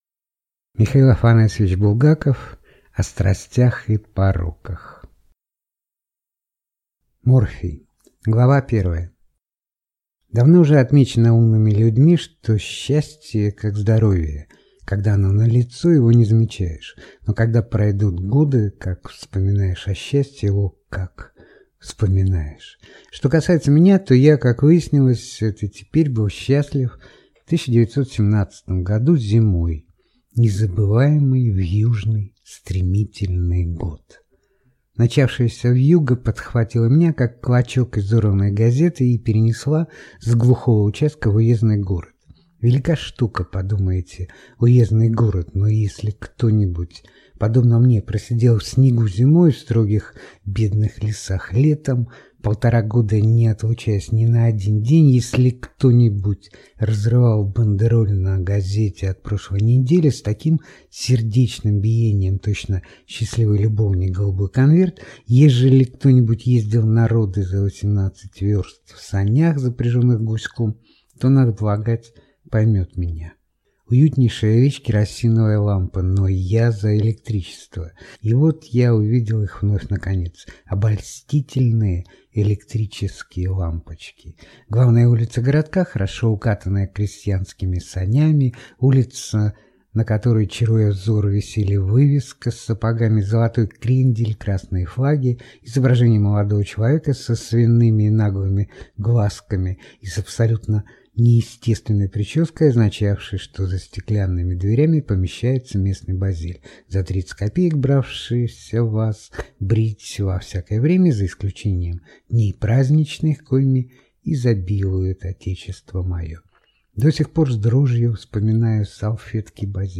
Аудиокнига О страстях и пороках (сборник) | Библиотека аудиокниг
Прослушать и бесплатно скачать фрагмент аудиокниги